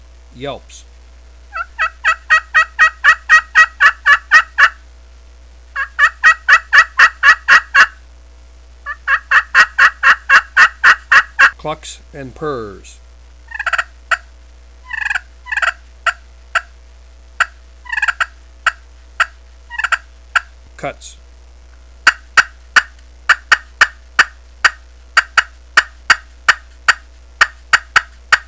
Listen to 28 seconds of yelps, clucks & purrs and cutts
From ear-piercing yelps to "make um gobble", to subtle yelps, clucks and purrs, a Power Crystal will do it all.
High-Frequency Glass Call